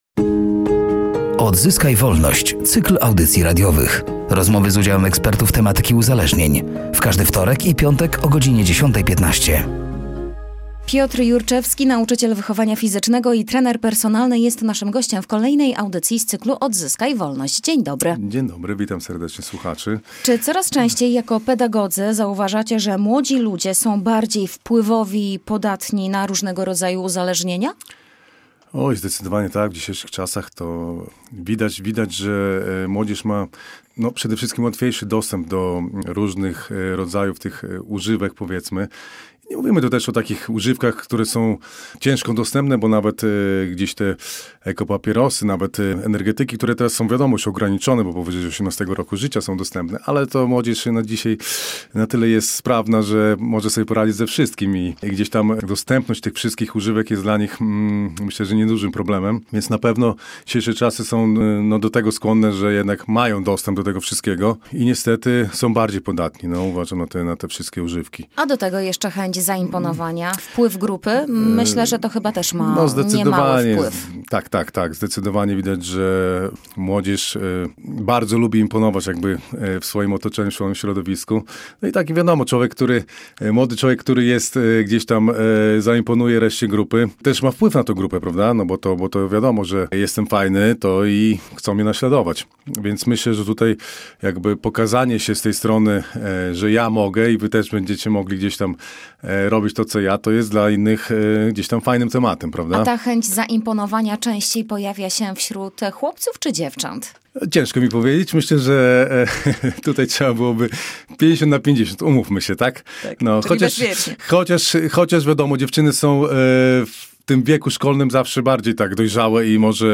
„Odzyskaj Wolność”, to cykl audycji radiowych poświęconych profilaktyce uzależnień wśród dzieci i młodzieży.